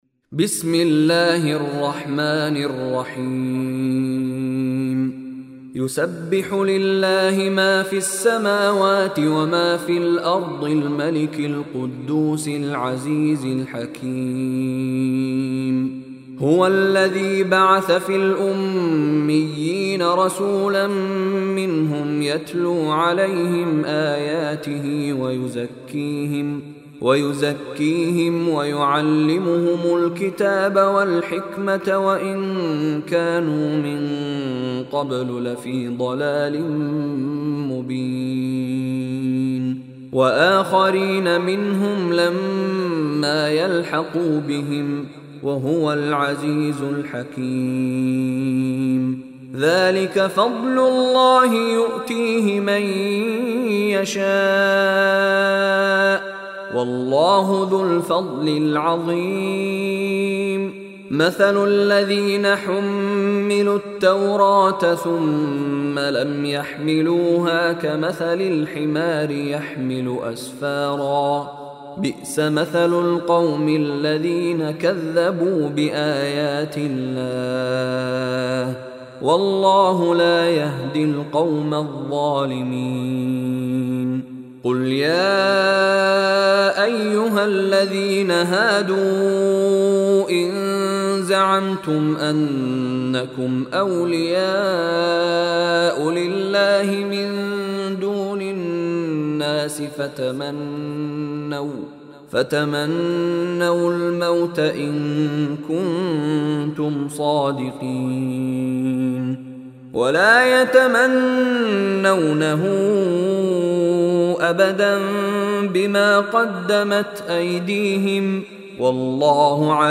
Surah Jumah Recitation by Mishary Rashid
Listen online and download beautiful Quran tilawat / Recitation of Surah Juma in the voice of Sheikh Mishary Rashid Alafasy.